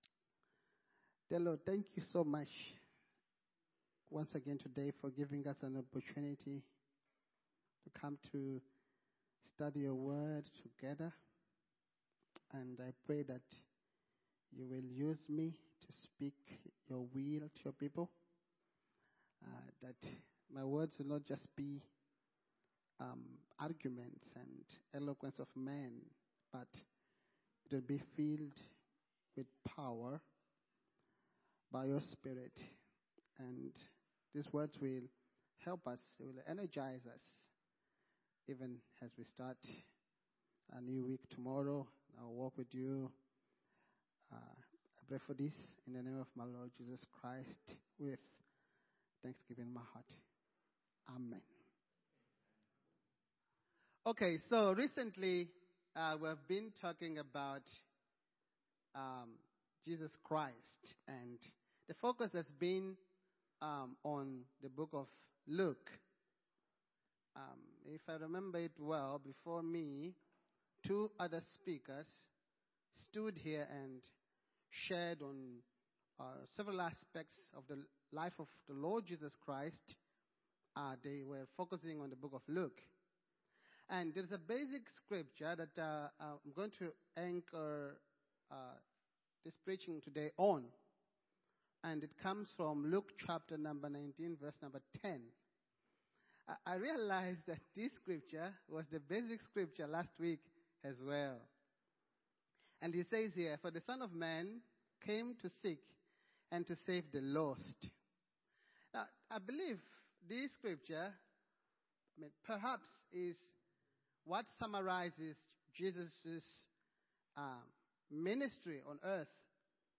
Sermon-2025-Dec-7.mp3